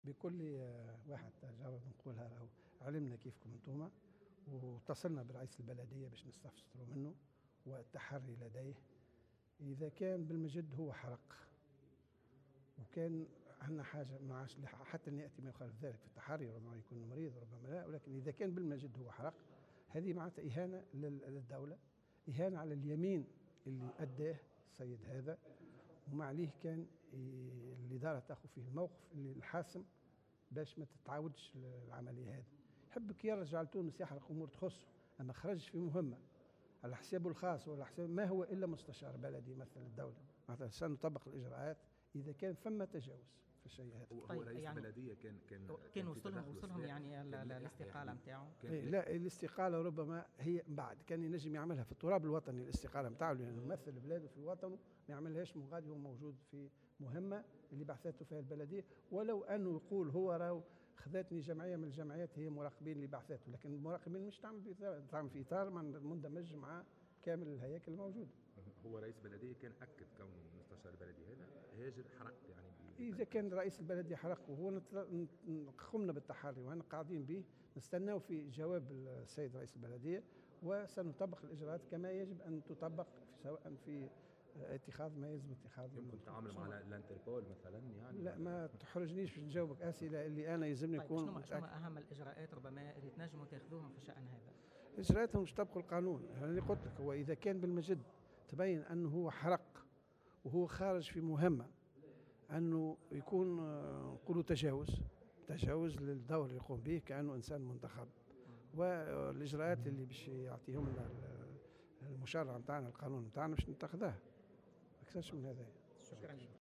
وقال في تصريح لمراسل "الجوهرة أف أم" إنه في حال ثبوت هذا التجاوز وارتكابه عملية "الحرقة" (هجرة غير شرعية) إلى ألمانيا فإنه سيتمّ اتخاذ الاجراءات اللاّزمة ضده لأن ما قام به يمثّل إهانة للدولة ومخالفة لليمين الدستوري الذي أداه عند تعيينه مستشارا للبلدية.